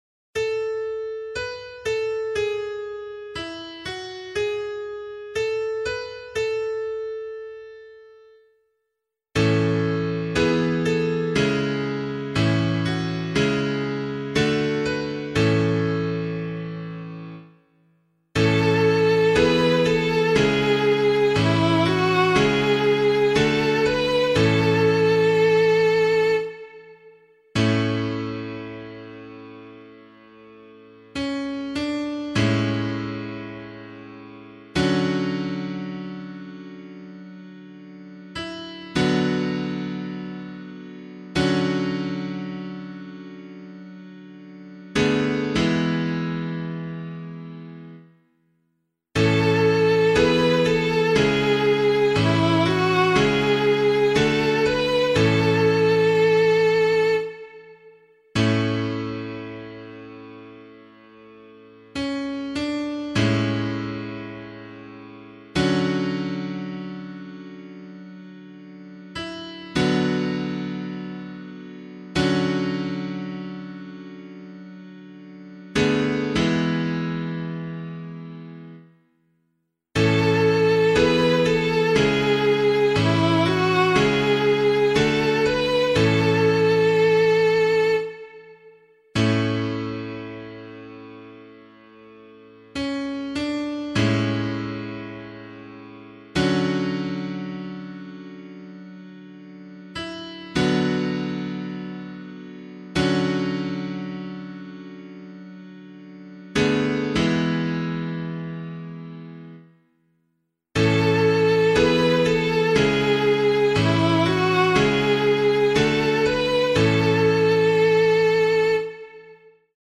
026 Easter 4 Psalm C [APC - LiturgyShare + Meinrad 8] - piano.mp3